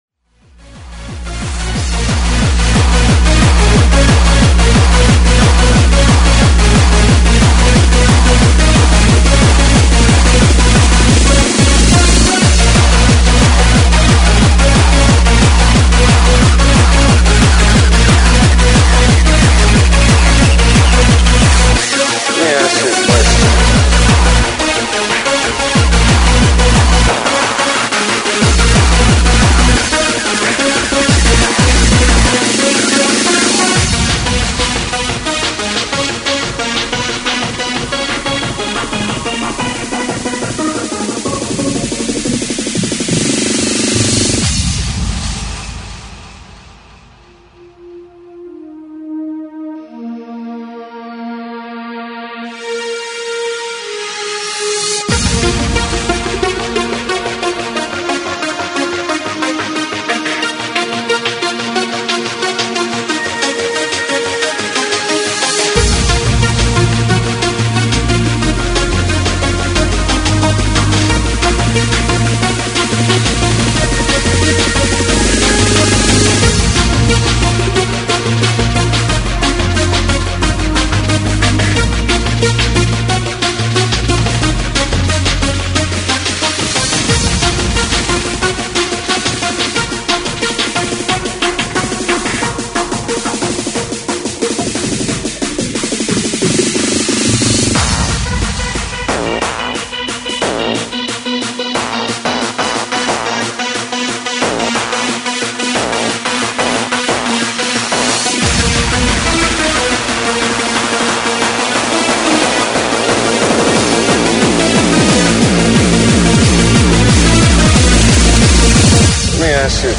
Freeform/Finrg/Hardcore